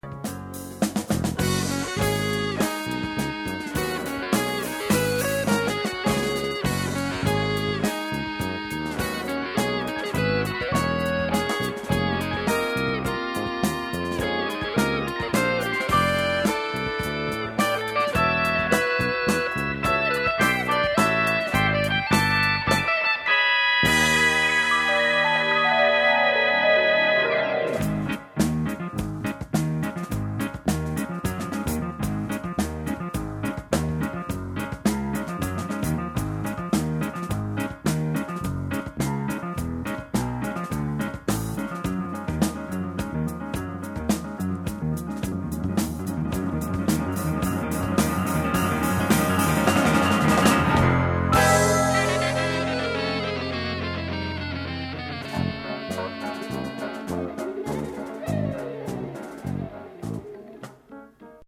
whimsical yet intelligent instrumental rock